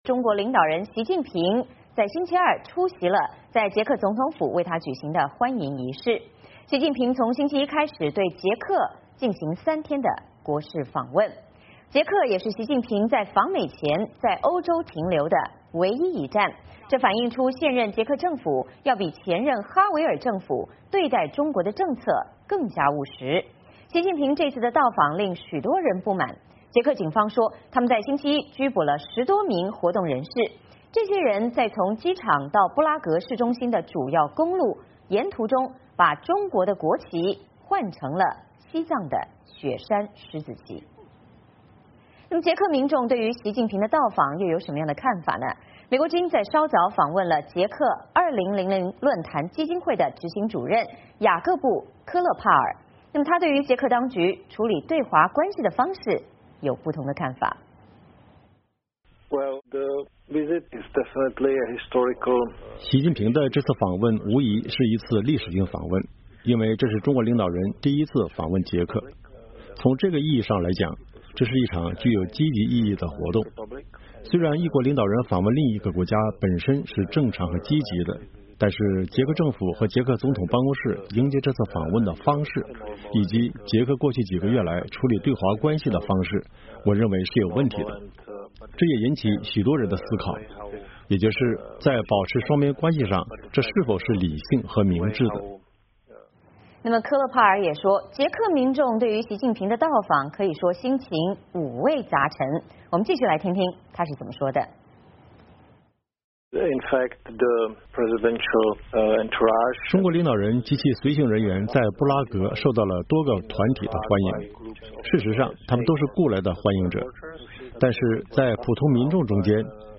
VOA连线：习近平到访，红旗飞扬，捷克民众五味杂陈